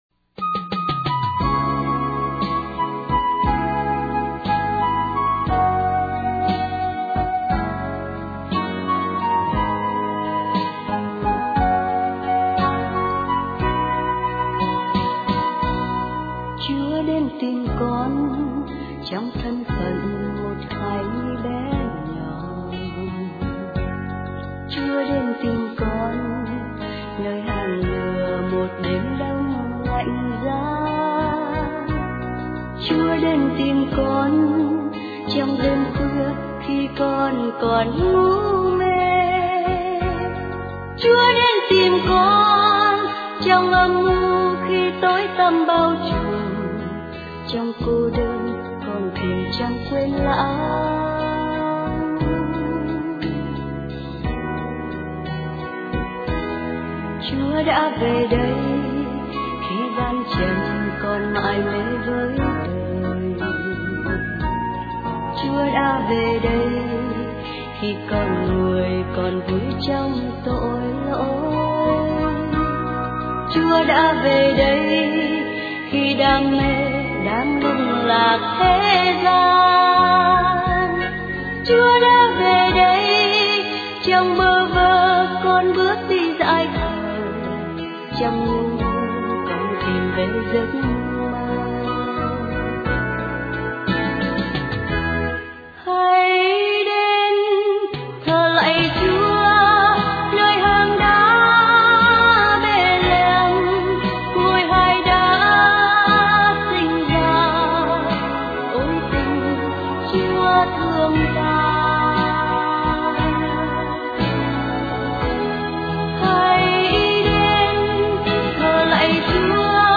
* Thể loại: Noel